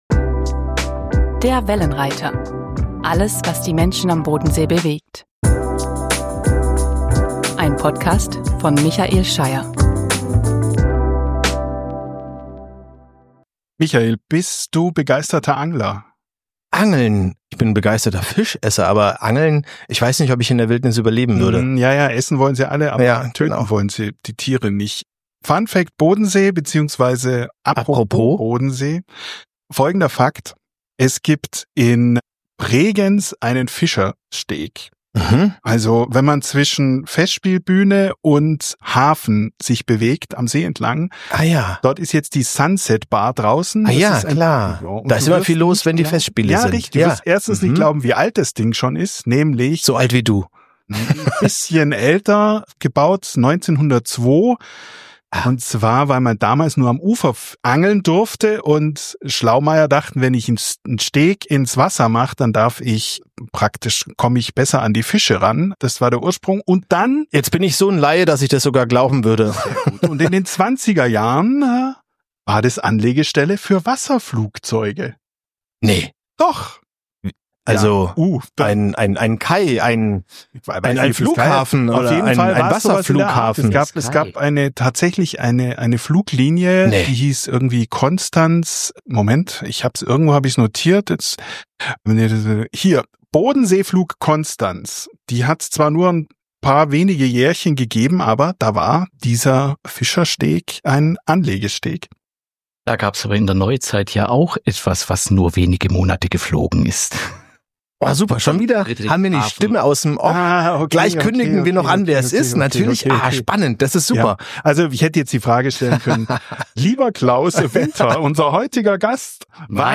Wir haben ihn in seinem Probenraum getroffen, wo er auch eine Hörprobe für den Wellenreiter live eingespielt hat.